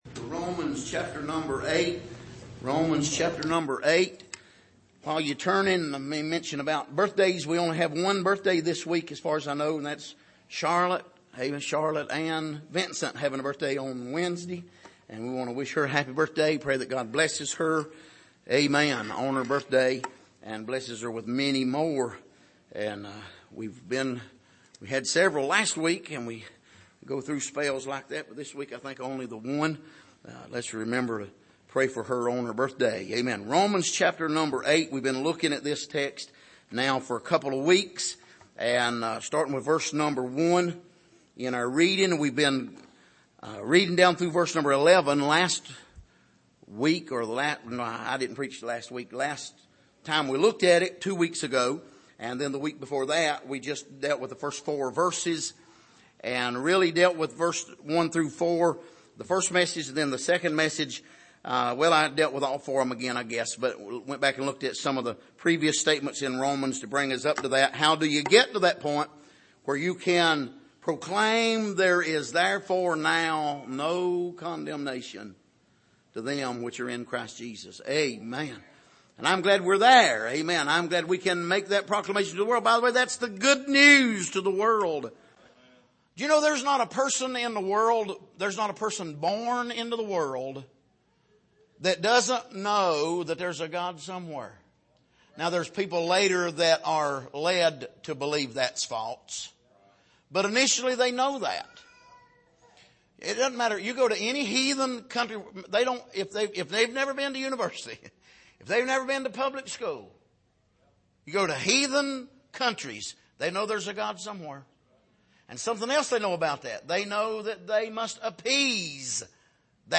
Passage: Romans 8:1-11 Service: Sunday Morning